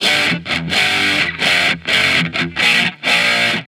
Guitar Licks 130BPM (13).wav